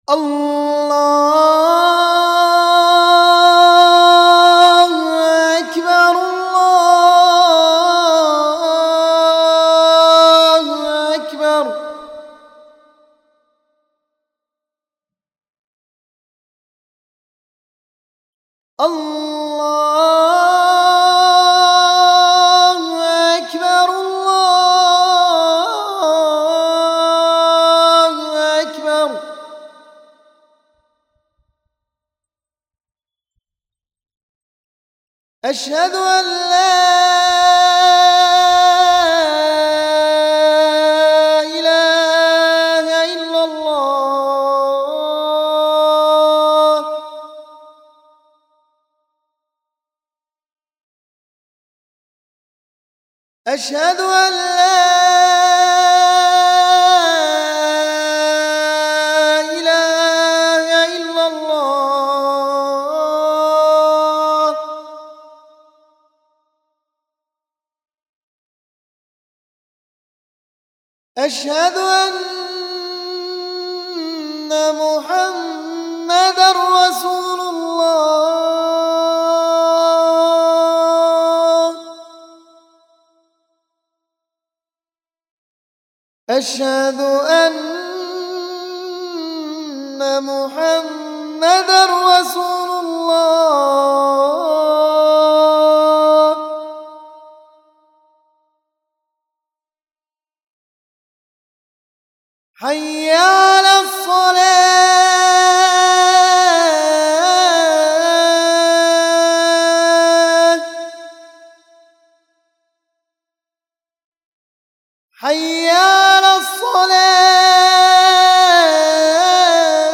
المكتبة الصوتية روائع الآذان المادة آذان
athan14.mp3